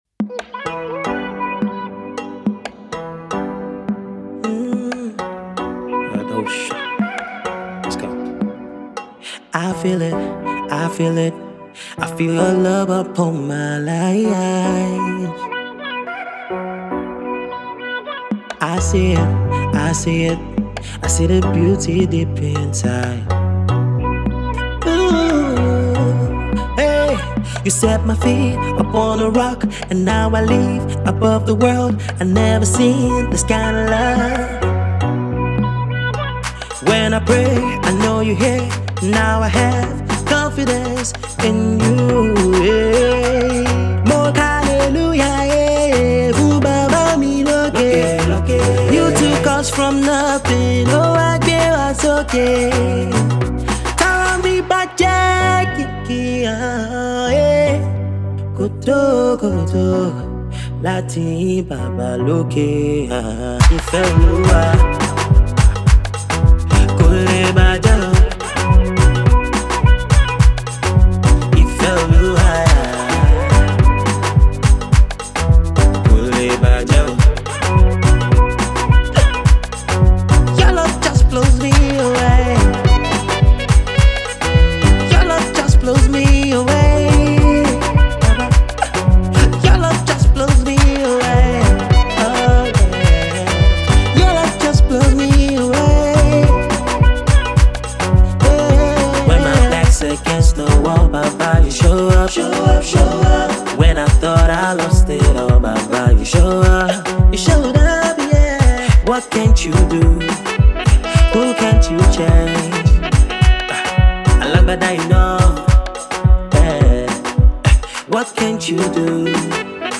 pop gospel sound
the perfect praise anthem